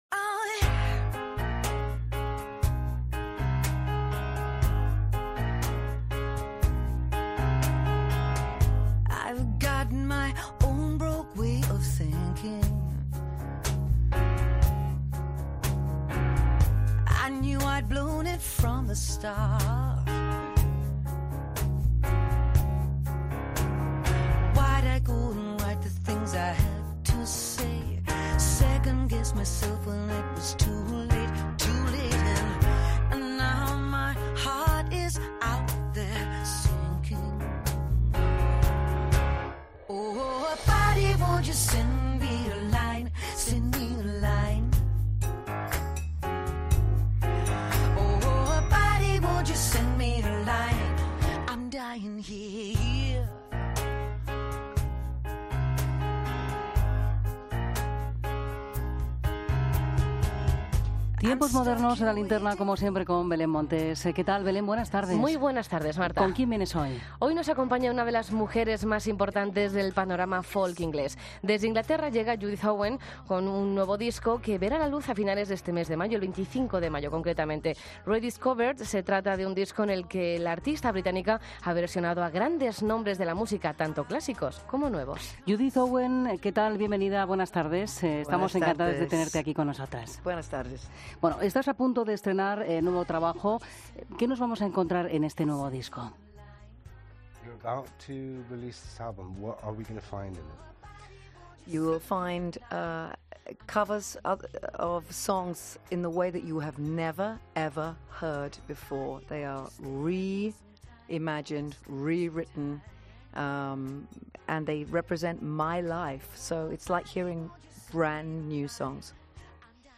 Entrevista a Judith Owen en La Linterna